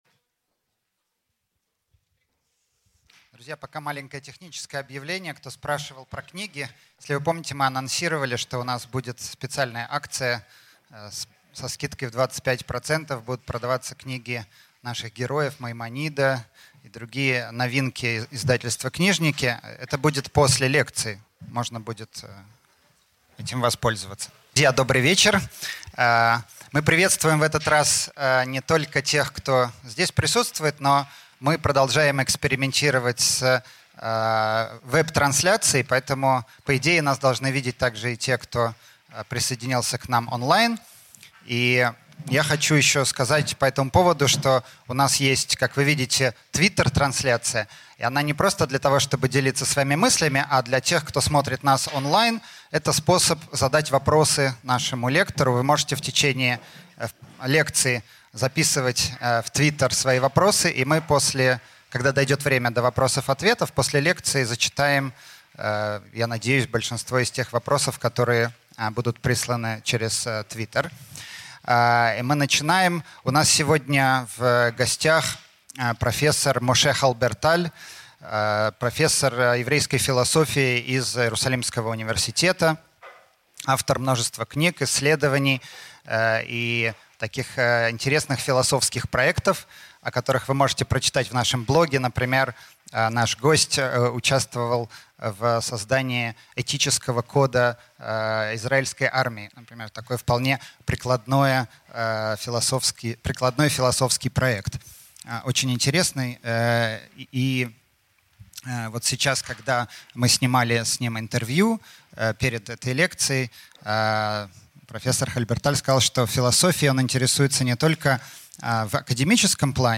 Аудиокнига Маймонид и идолы разума | Библиотека аудиокниг
Прослушать и бесплатно скачать фрагмент аудиокниги